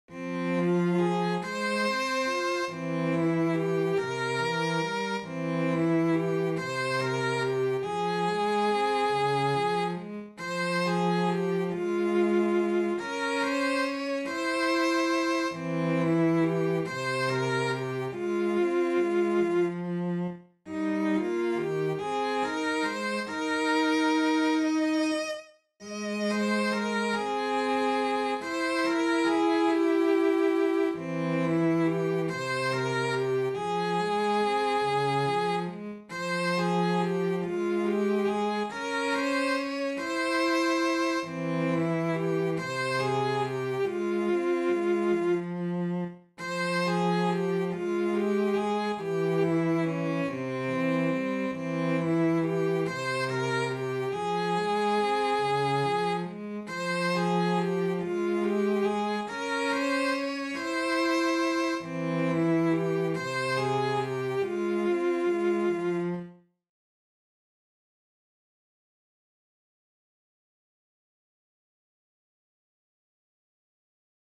Talven-selka-taittui-sellot.mp3